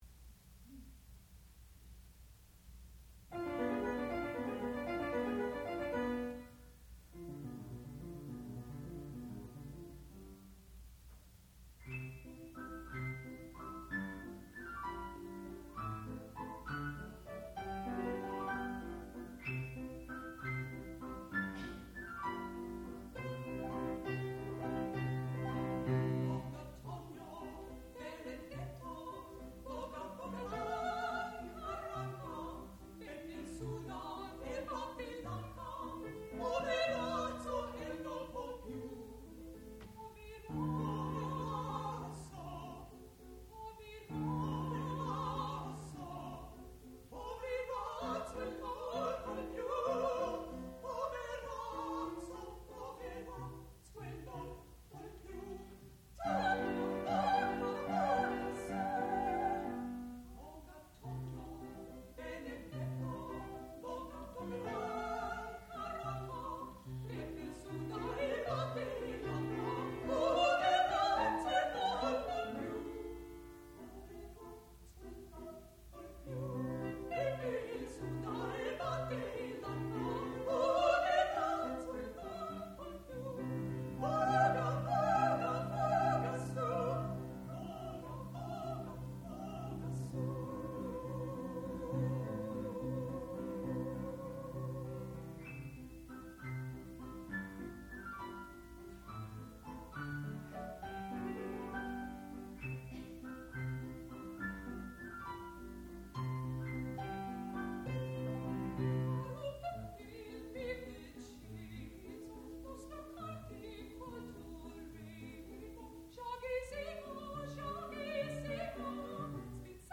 sound recording-musical
classical music
mezzo-soprano
piano
Graduate Recital